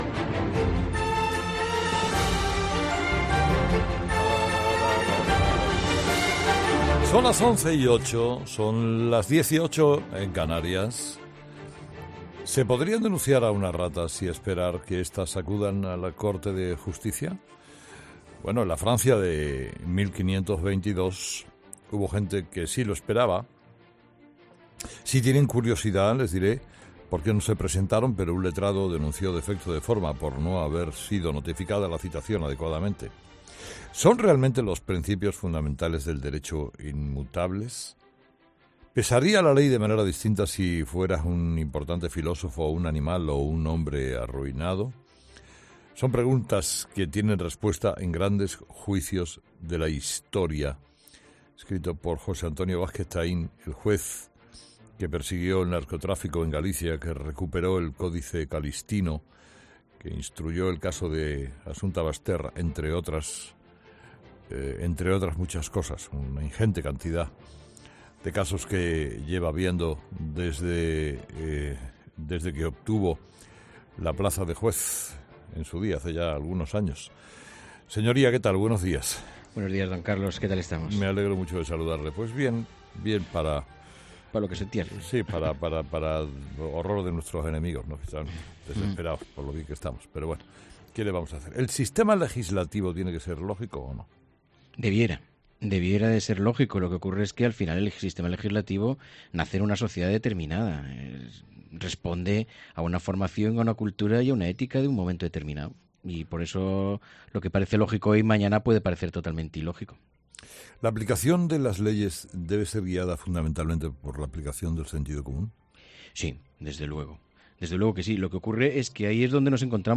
Escucha la entrevista al juez Vázquez Taín en Herrera en COPE